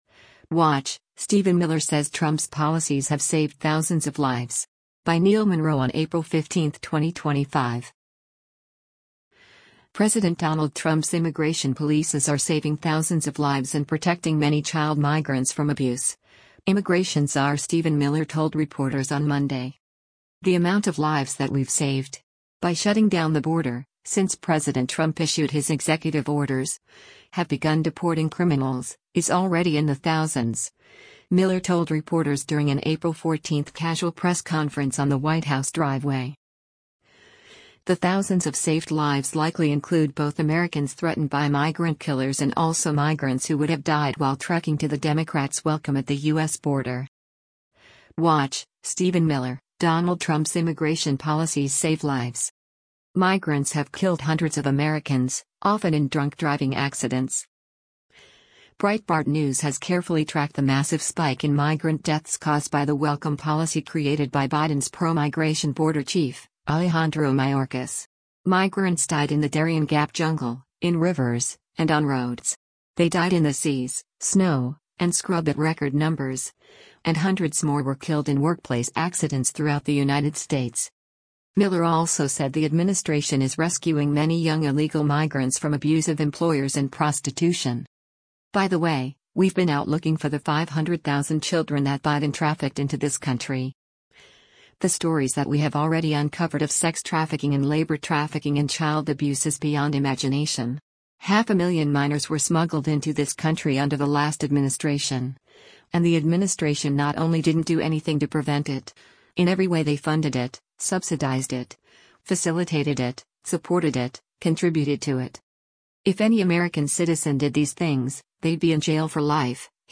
“The amount of lives that we’ve saved… by shutting down the border, since President Trump issued his executive orders, have begun deporting criminals, is already in the thousands,” Miller told reporters during an April 14 casual press conference on the White House driveway.